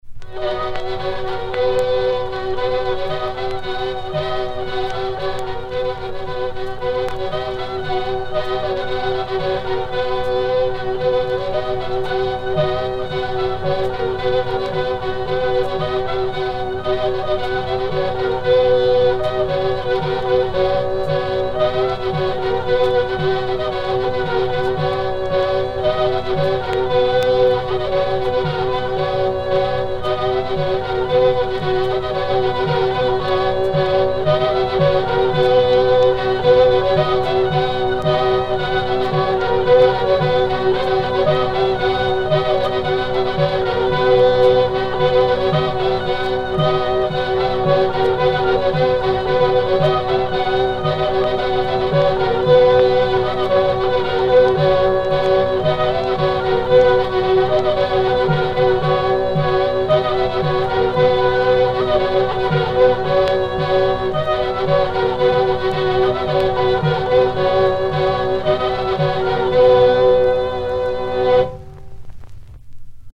vielleux enregistrés le 15 septembre 1948 à Paris
danse : branle
Pièce musicale éditée